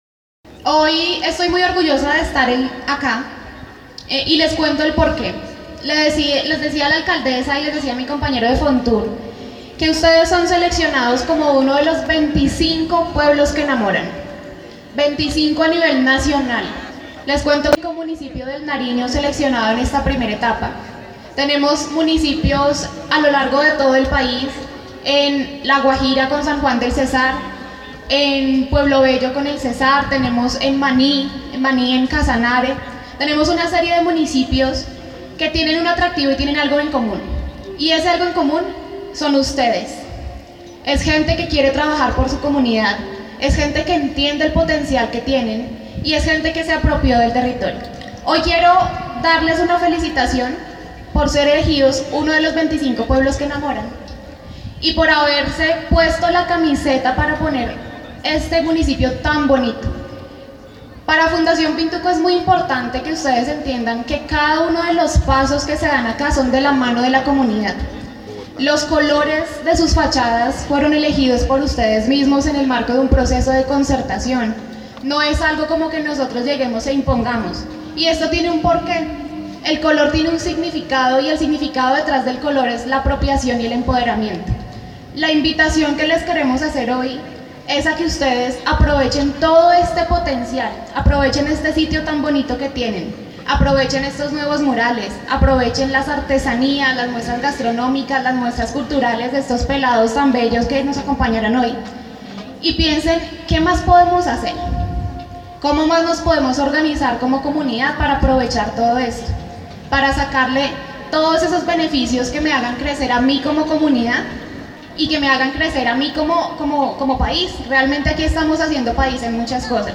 En el parque de la vereda Ingenio Centro este martes en la mañana se realizó el lanzamiento del programa “Sandoná pueblo que enamora, con arte pintura y color”.